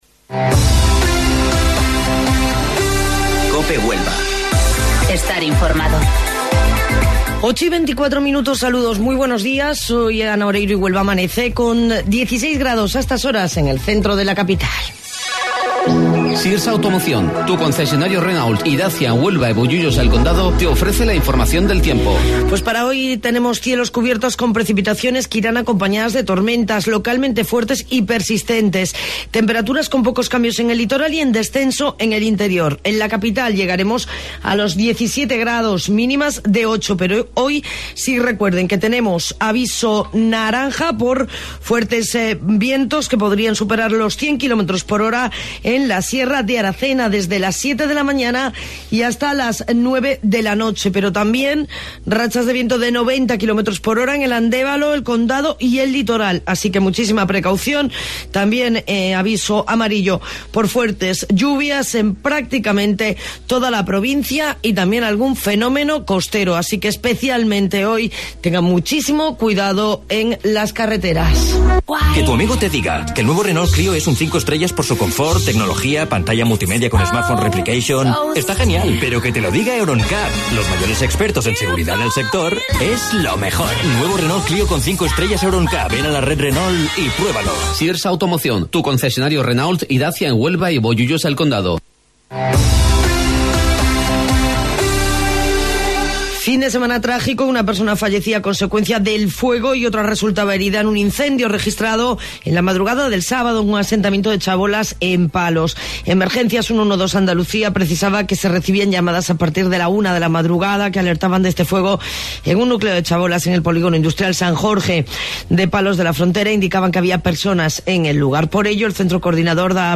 AUDIO: Informativo Local 08:25 del 16 de Diciembre